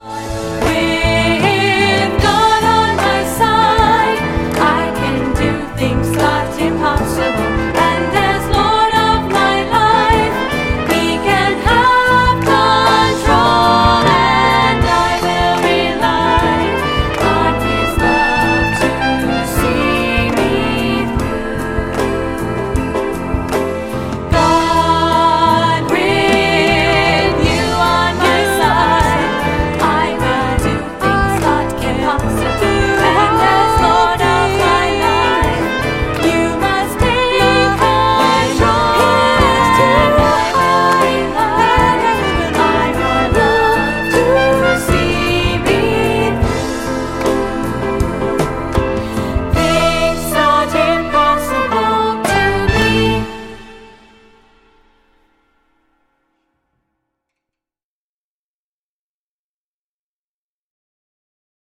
Trumpet Feature
Trumpet Feature on album track.